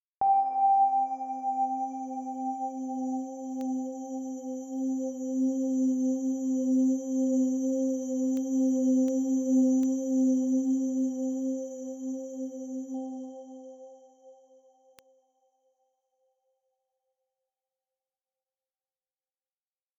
ambientBell
ambient bell bleep calm chime ding evolving sound effect free sound royalty free Nature